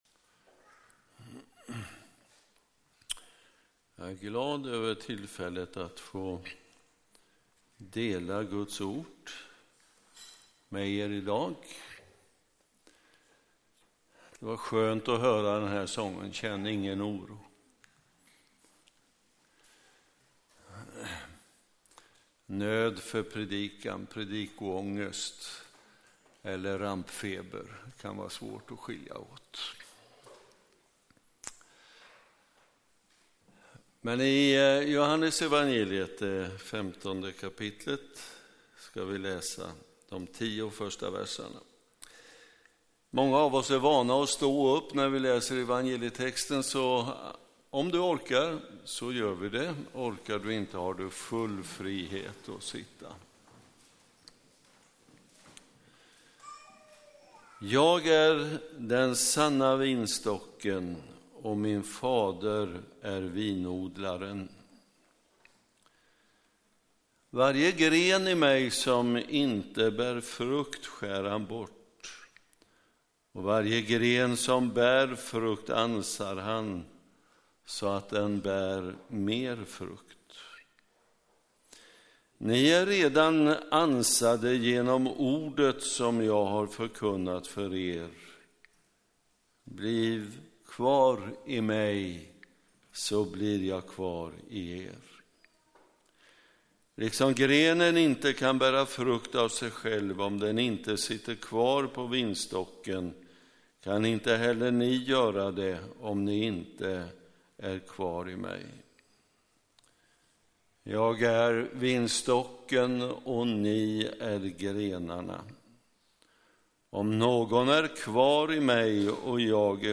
predikar i den ekumeniska gudstjänsten 4 aug